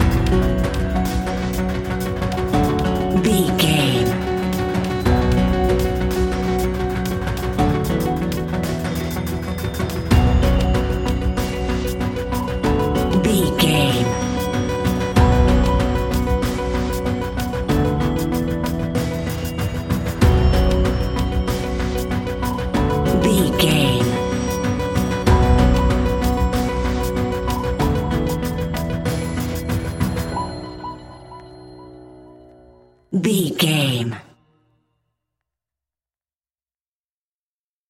In-crescendo
Thriller
Aeolian/Minor
ominous
dark
haunting
eerie
electronic music
Horror Pads
Horror Synths